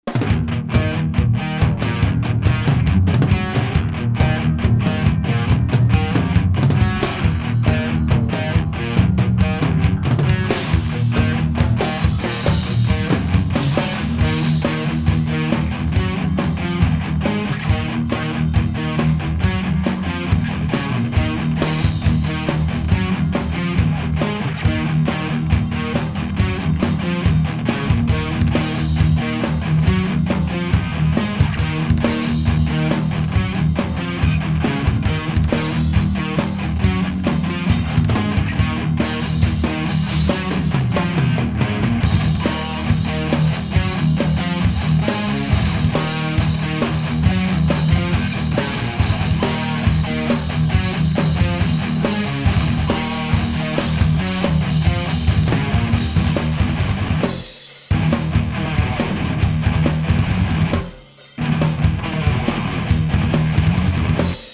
Pre vsetkych priaznivcov internetoveho mudrovania a pocuvania a porovnavania ukazok som pripravil 6 vzoriek kvazy toho isteho - 2 beglajtove gitary + basa + bicie. Rozdiel je iba v gitarach, boli pouzite viacere gitarove aparaty alebo modeling.